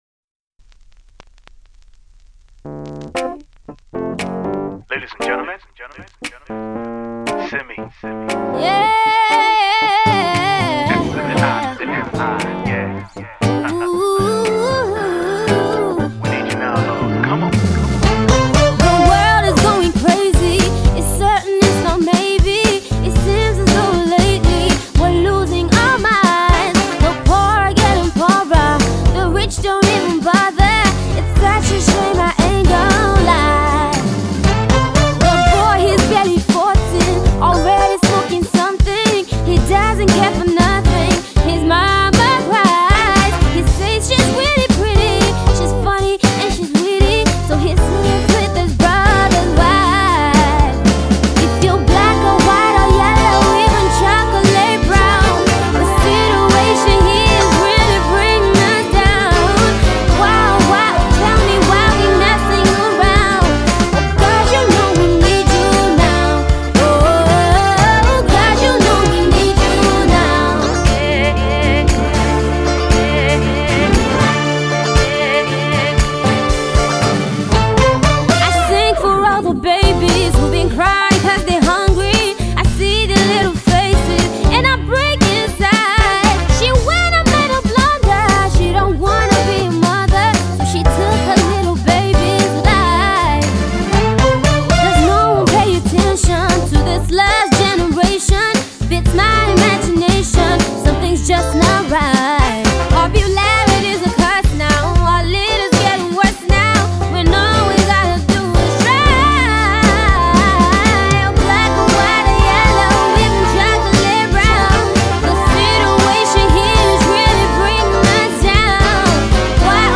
Pop/Jazz/Soul singer/songwriter
With her peculiar voice